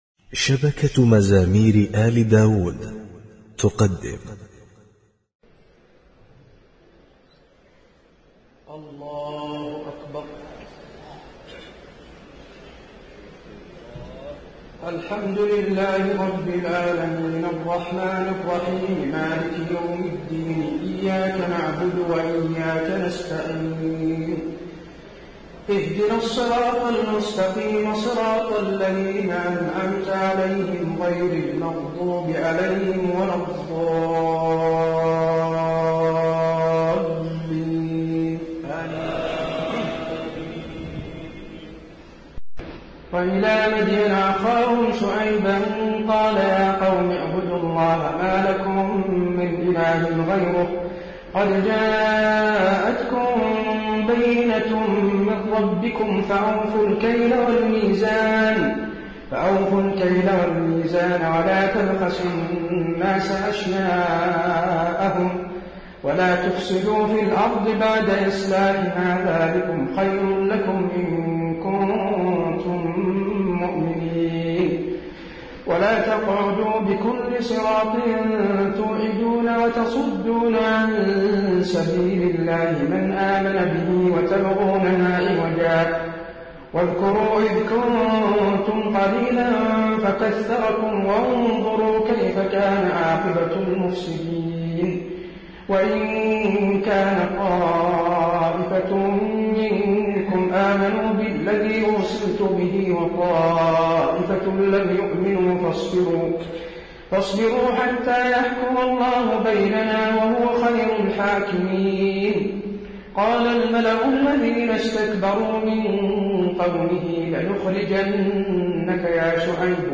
تهجد ليلة 27 رمضان 1432هـ من سورة الأعراف (85-206) Tahajjud 27 st night Ramadan 1432H from Surah Al-A’raf > تراويح الحرم النبوي عام 1432 🕌 > التراويح - تلاوات الحرمين